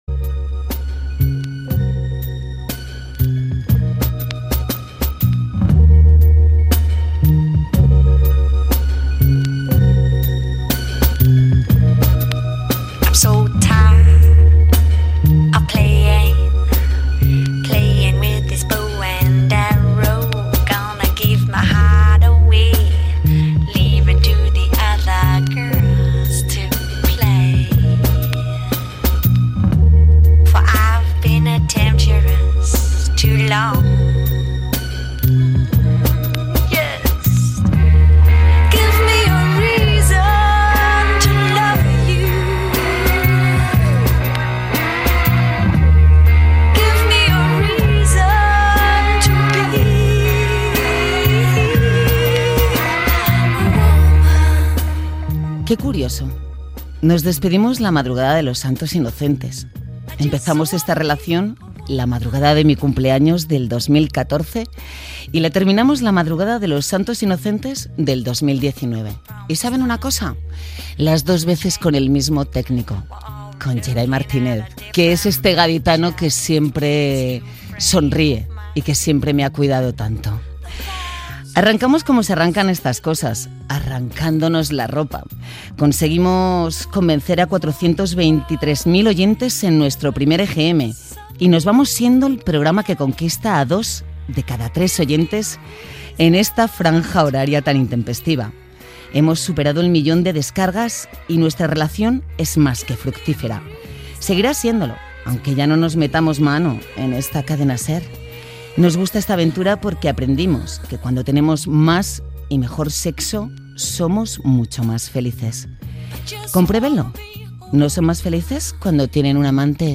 Sintonia, presentació de l'última edició del programa amb esment al tècnic de so, les dades d'audiència, anunci de l'inici del pòdcast "Con todos dentro", adreça de Twitter, records del programa i lectura d'alguns missatges de l'audiència Gènere radiofònic Entreteniment